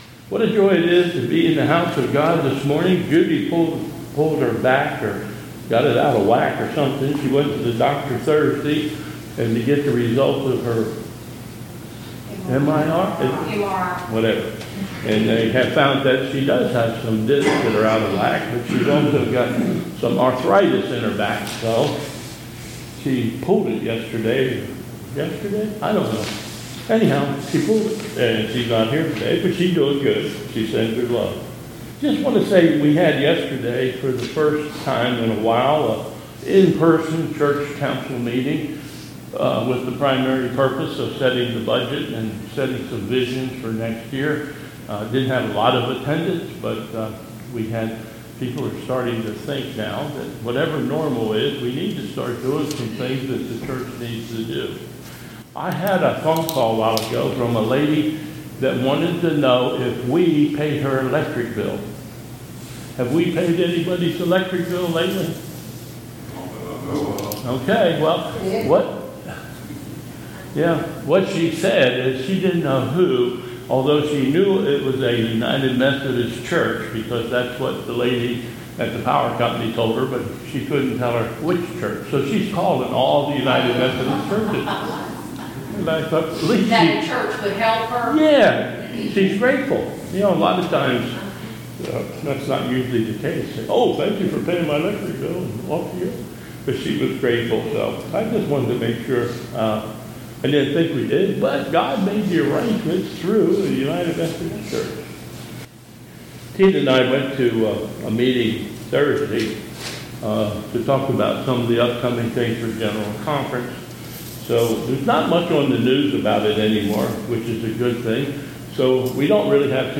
2022 Bethel Covid Time Service
Other Announcements